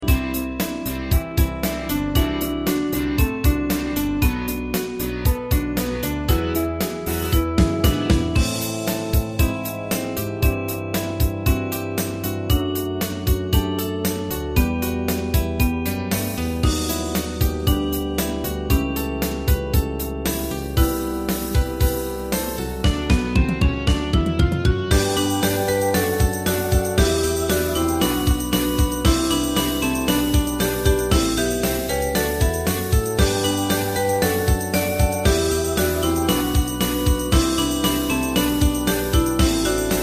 大正琴の「楽譜、練習用の音」データのセットをダウンロードで『すぐに』お届け！
カテゴリー: アンサンブル（合奏） .